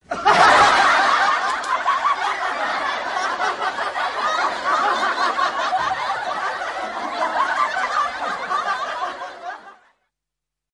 SFX综艺常见笑声高效音效下载
SFX音效